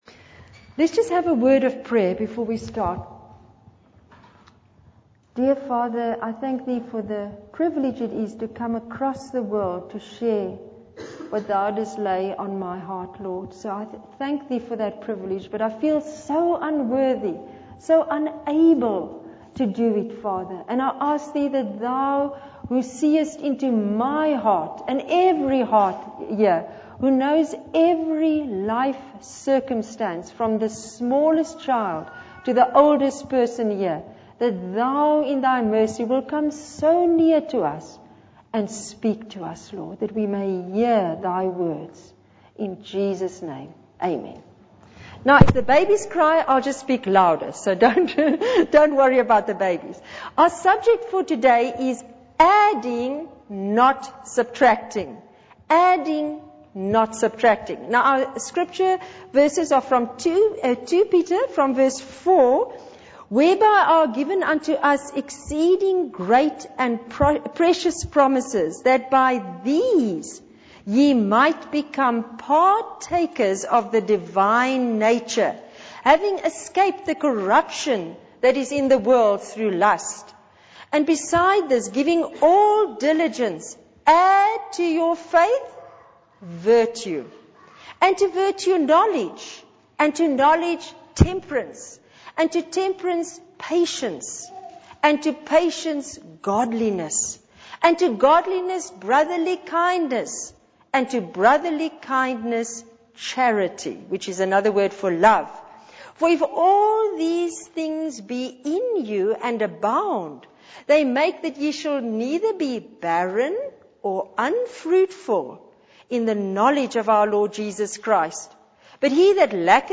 In this sermon, the speaker shares a story about a man who rescued people clinging to a tree by using a helicopter and a rope. The speaker emphasizes the importance of doing the right thing with the right attitude.